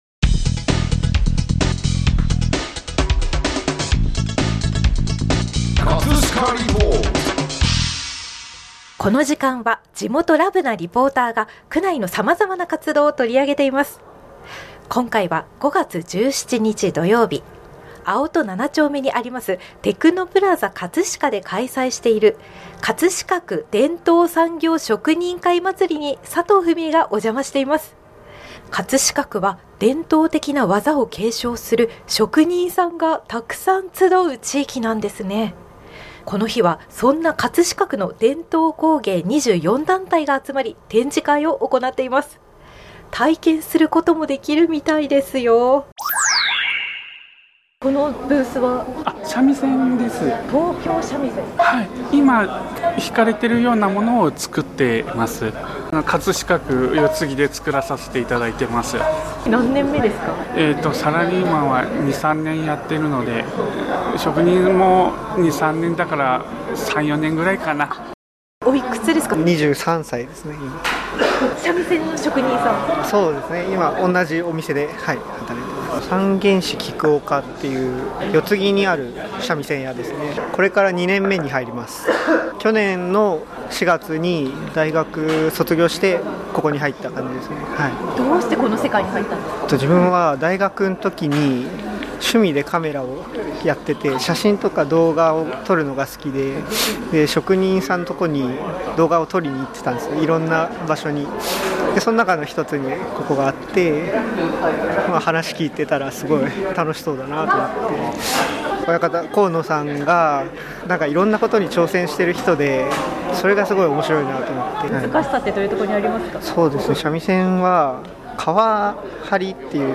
インタビュー時も漫才のような掛け合いばかり…！